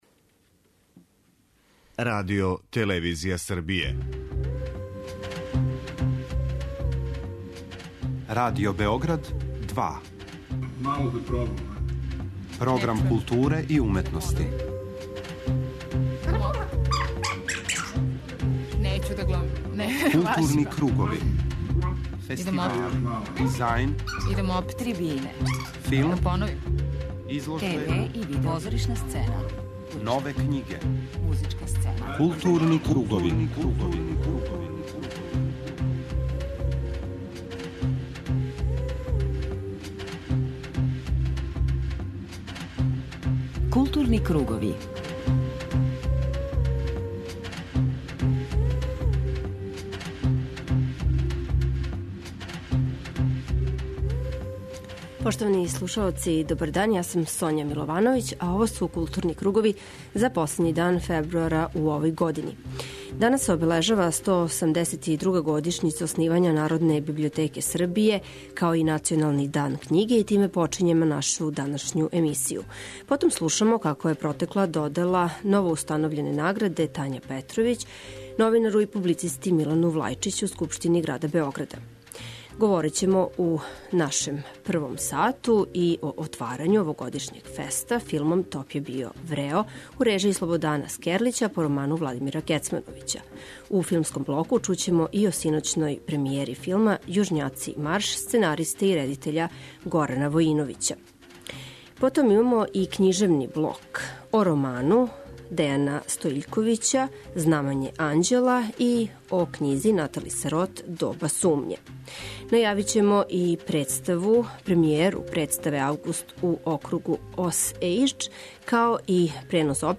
преузми : 53.96 MB Културни кругови Autor: Група аутора Централна културно-уметничка емисија Радио Београда 2.